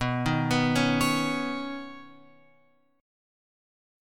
Badd9 chord {7 6 9 6 x 9} chord